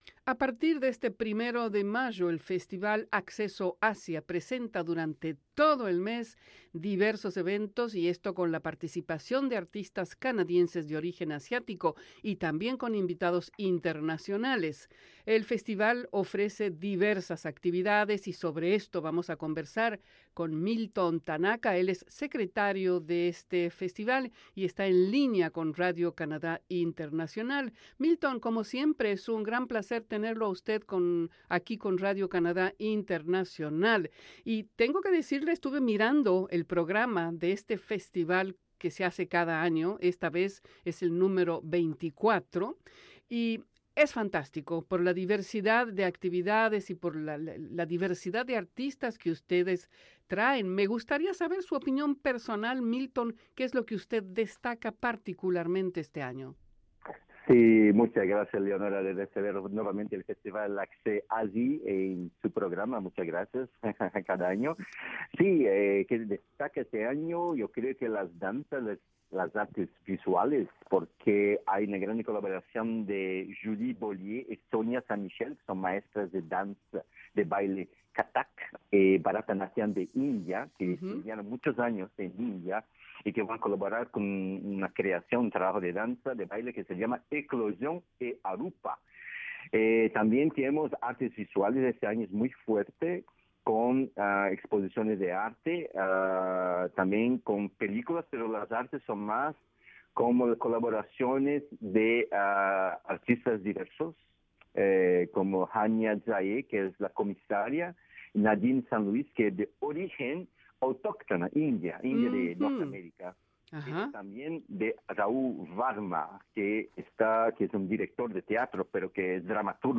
Conversamos con